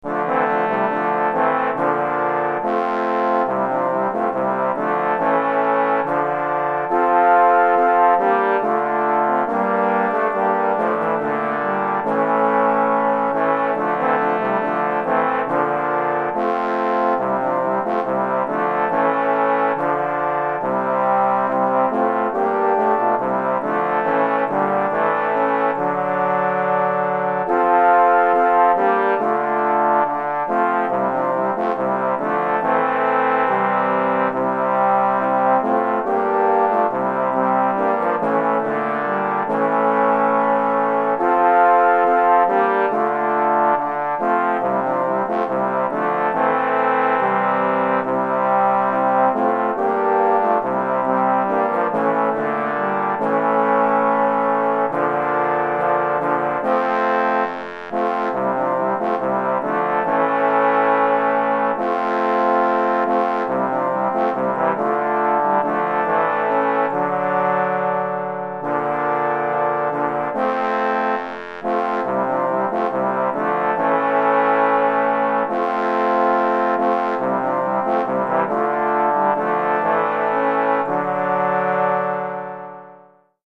3 Trombones et Trombone Basse